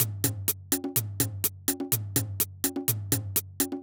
Drumloop 125bpm 05-B.wav